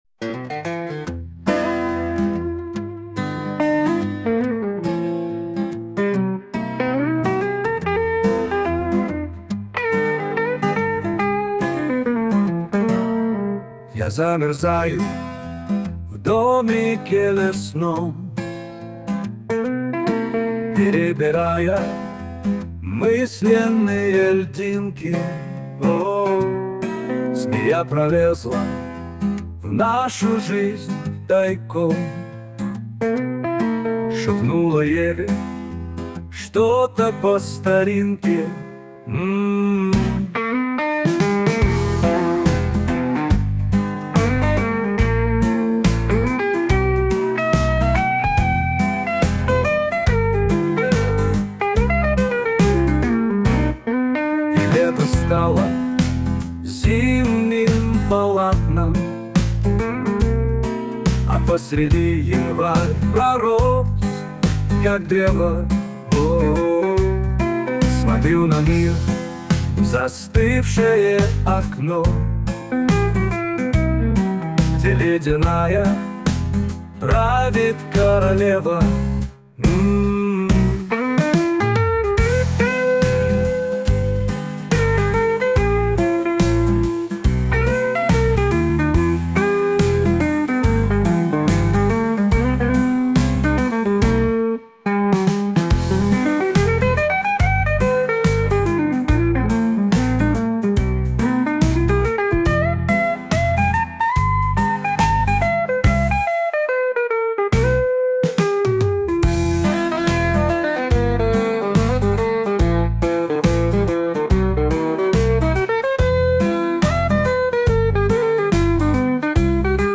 Блюз (1232)